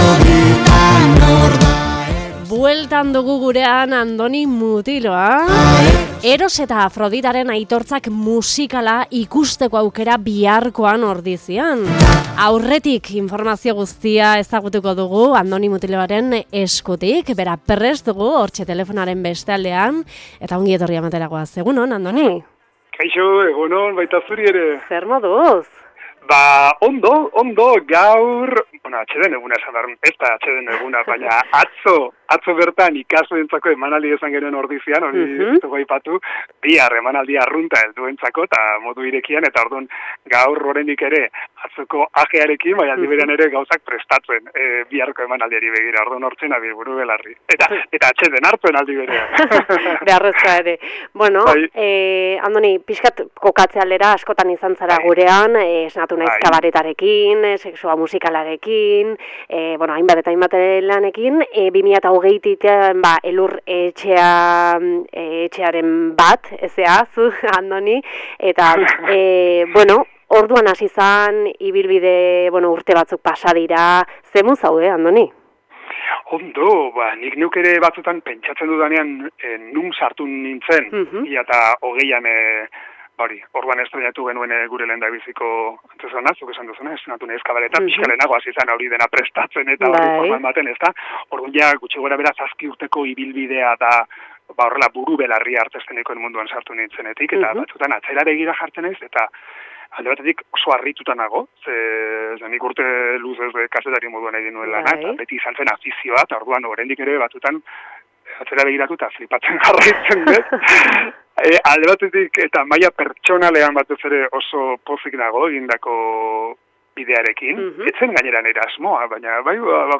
IRRATSAIOAK